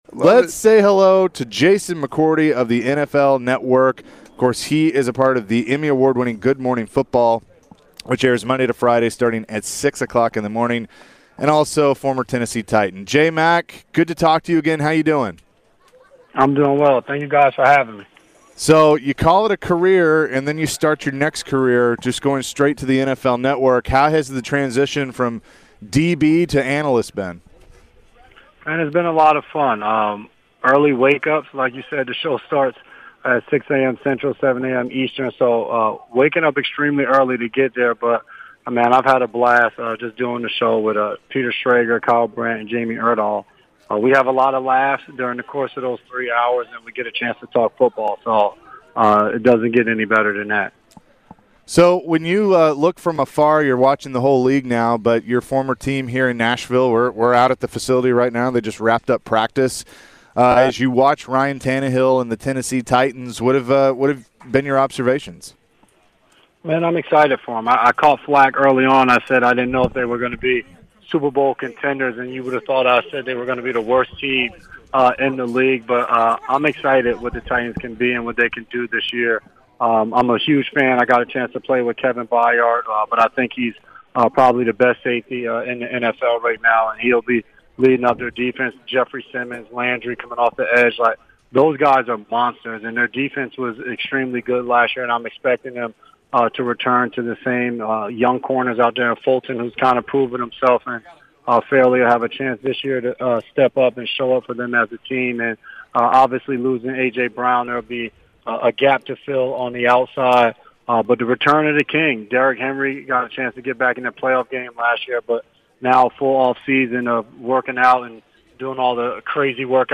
Jason McCourty interview (8-17-22)